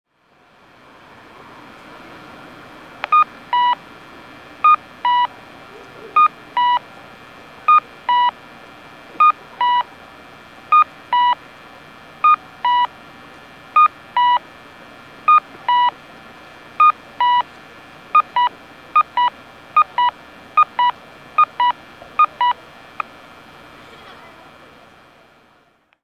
駅前本町交差点(大分県別府市)の音響信号を紹介しています。